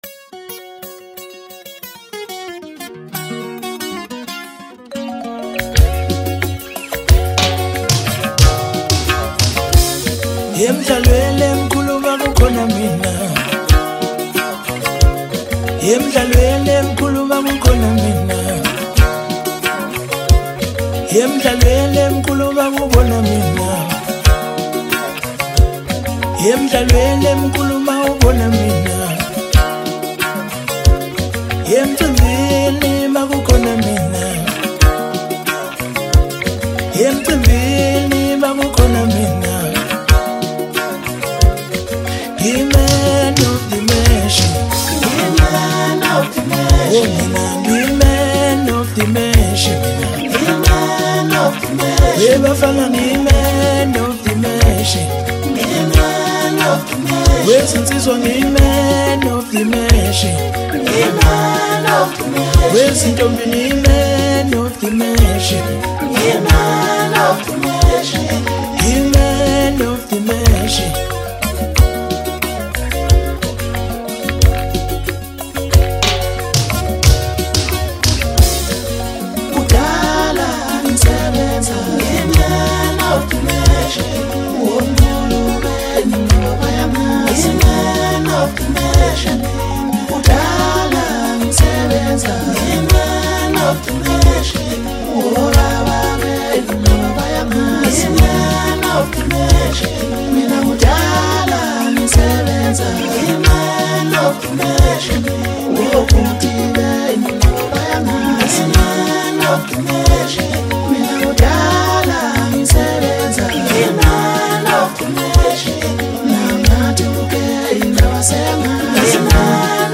Home » Maskandi » Maskandi Music